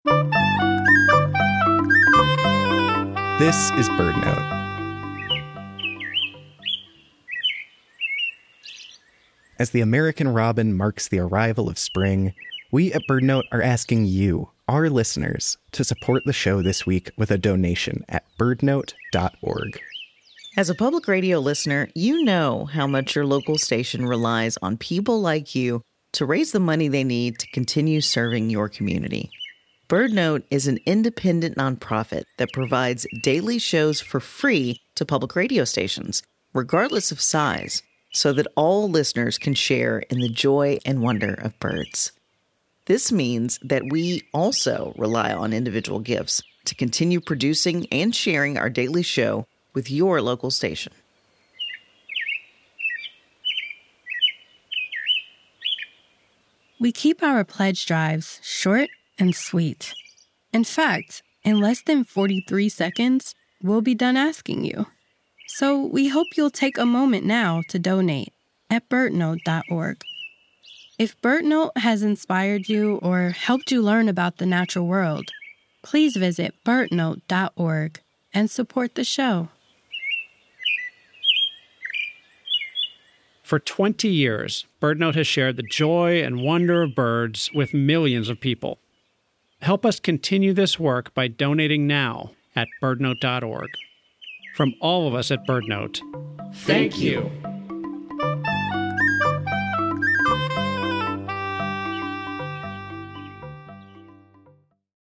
We know you’ve got a busy schedule, which is why the team at BirdNote produces fun, sound-rich episodes about the natural world in less than 2 minutes each day.